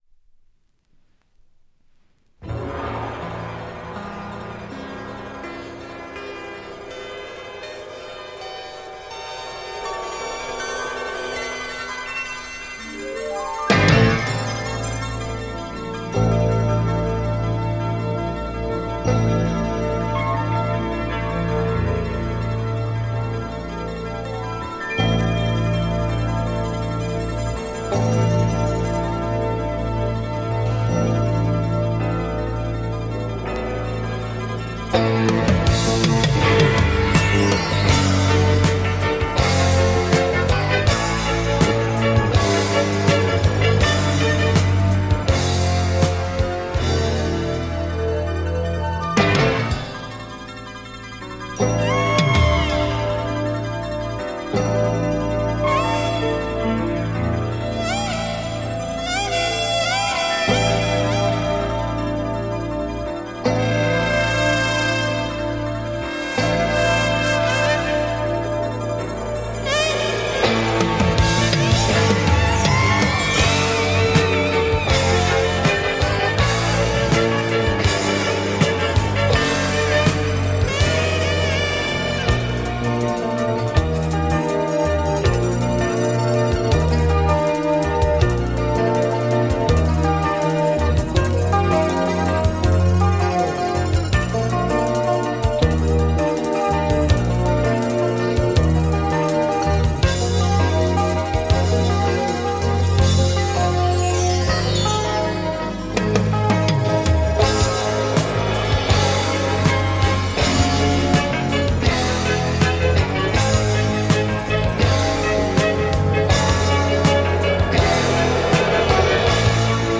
French library 80s electro minimal LP
80s electro instrumental library.